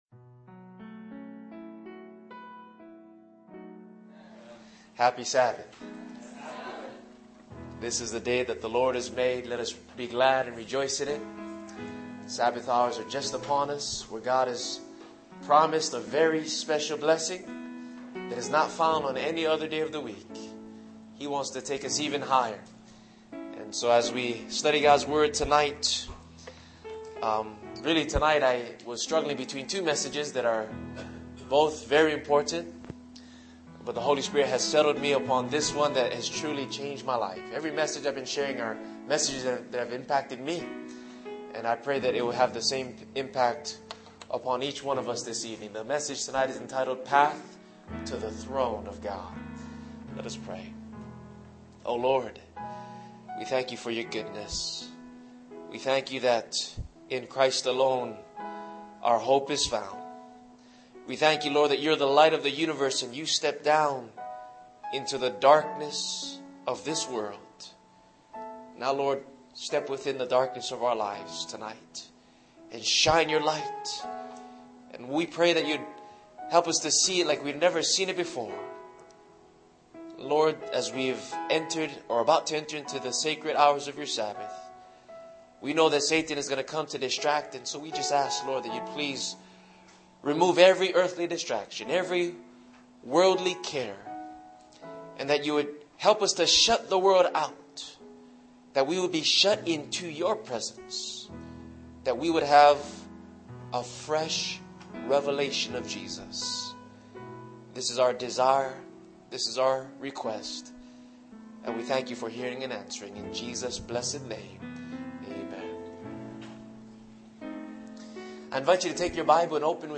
during the West Coast Camp Meeting 2013